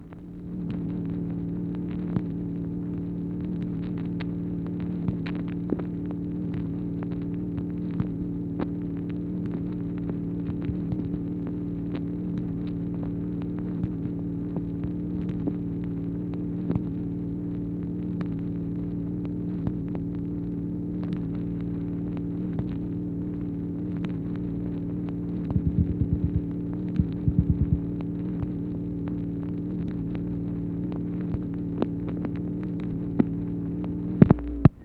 MACHINE NOISE, January 8, 1964
Secret White House Tapes